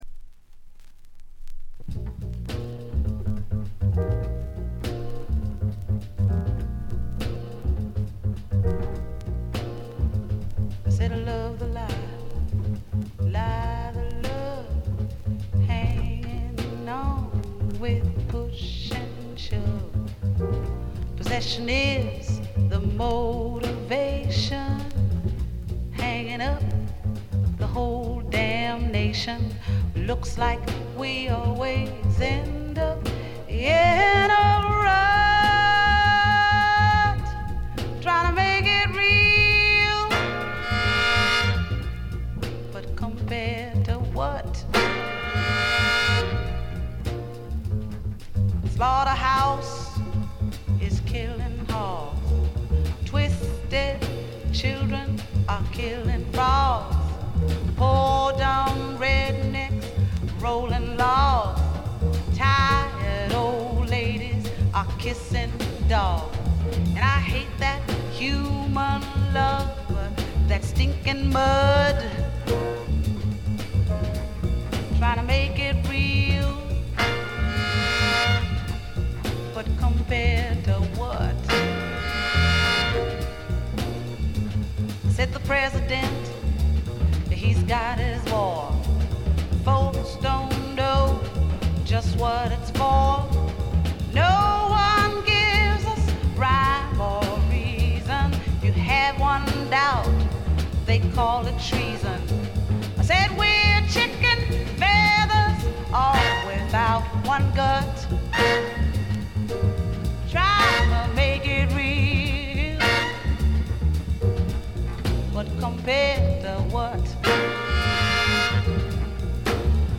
軽微なバックグラウンドノイズのみ。
才媛ぶりを見事に発揮したジャズ色の強いアルバム。
個人的には冒頭のウッドベースの音一発でやられてしまいます。
試聴曲は現品からの取り込み音源です。
Piano, Vocals